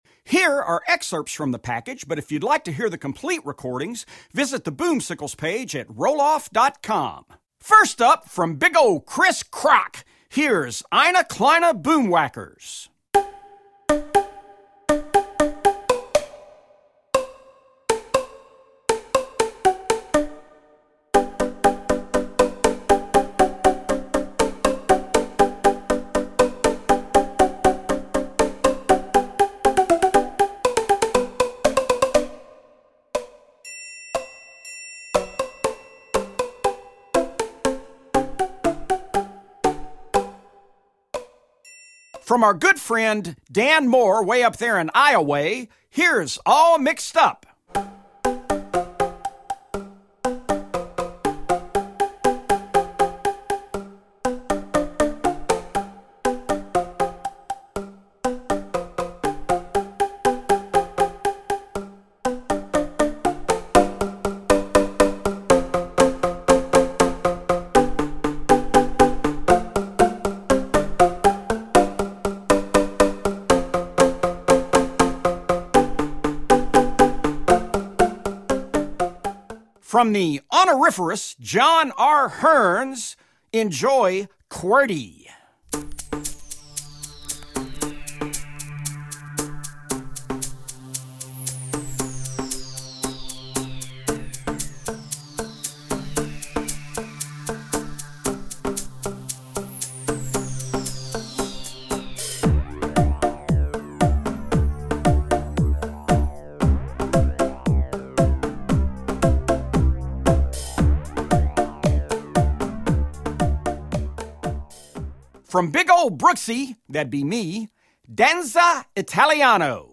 Voicing: Percussion Sextet